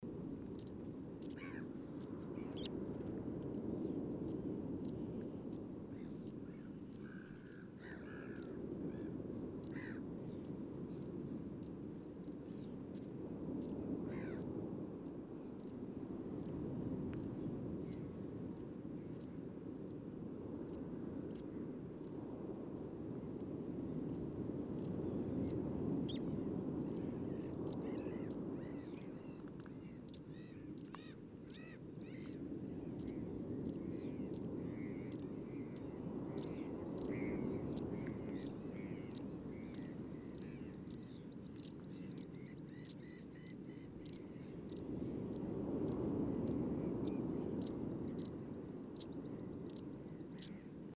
PFR07304, 120710, Meadow Pipit Anthus pratensis, alarm calls, adult, Cley Marshes, UK